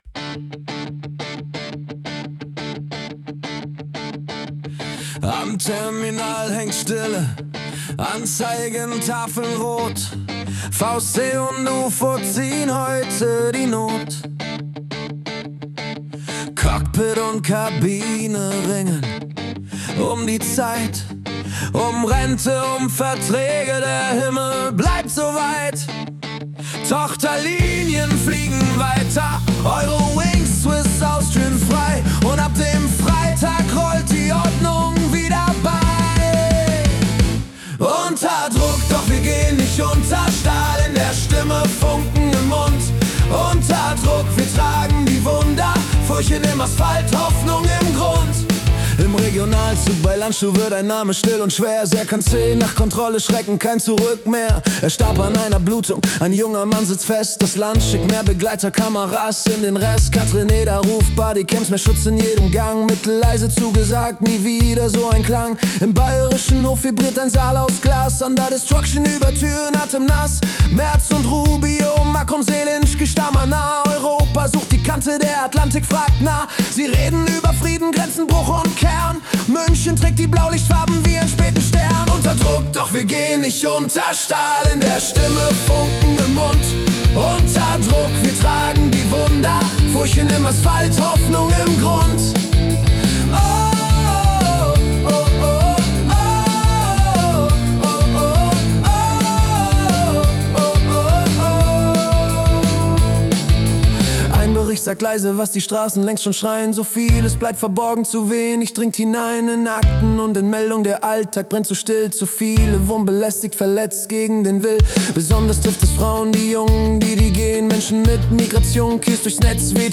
Februar 2026 als Rock-Song interpretiert.